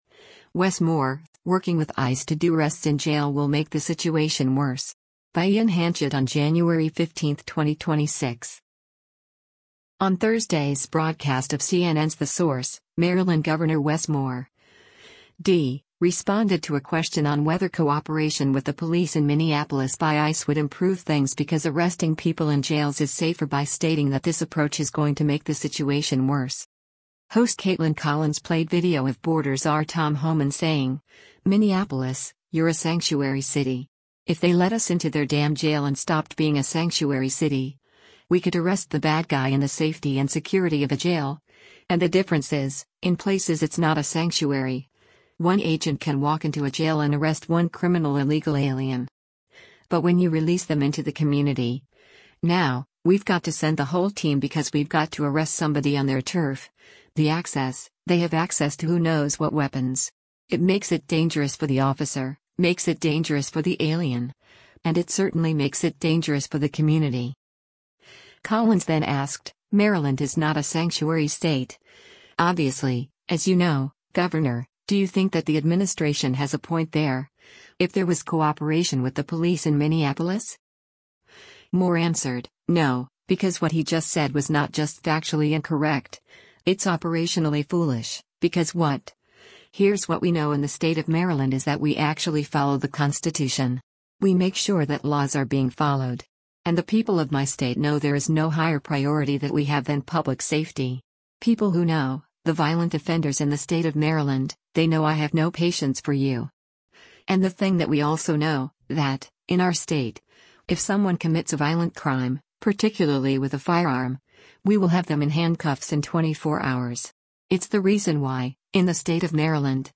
On Thursday’s broadcast of CNN’s “The Source,” Maryland Gov. Wes Moore (D) responded to a question on whether cooperation with the police in Minneapolis by ICE would improve things because arresting people in jails is safer by stating that this approach is “going to make the situation worse.”
Host Kaitlan Collins played video of Border Czar Tom Homan saying, “Minneapolis, you’re a sanctuary city.